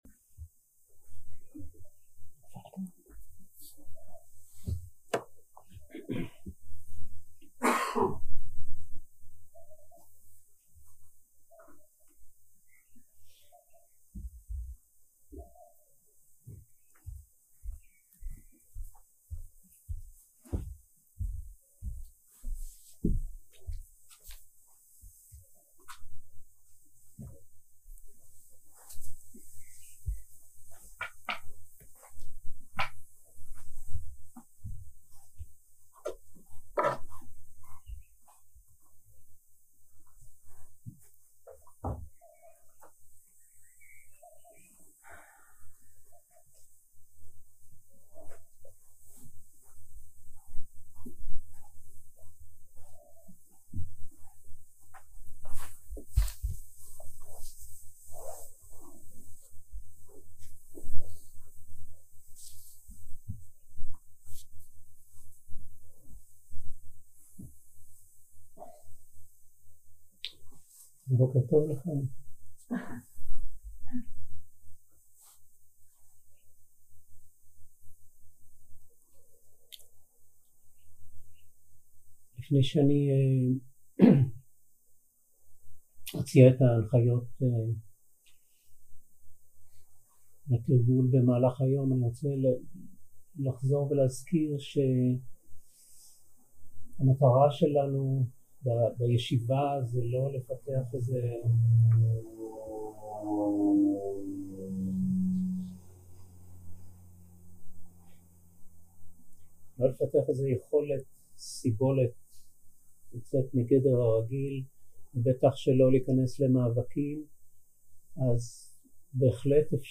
יום 3 - הקלטה 4 - בוקר - הנחיות למדיטציה - חמשת האורחים.
סוג ההקלטה: שיחת הנחיות למדיטציה